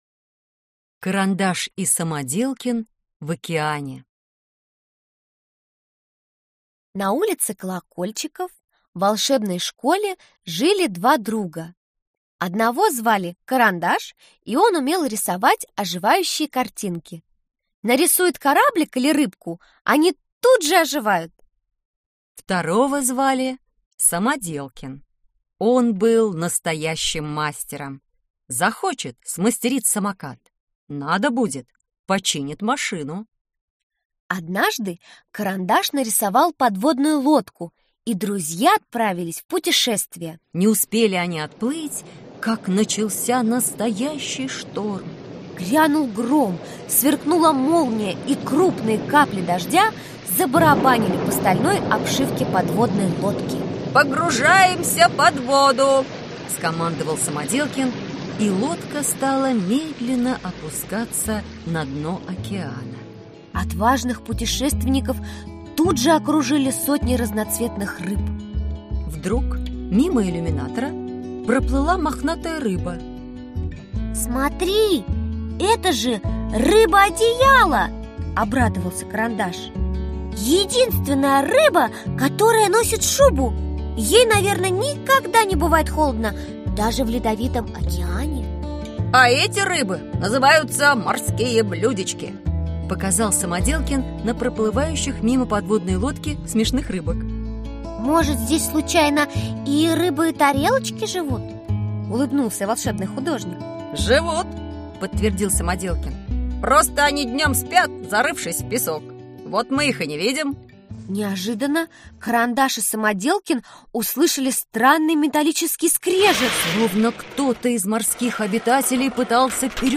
Аудиокнига Сказки про Карандаша и Самоделкина | Библиотека аудиокниг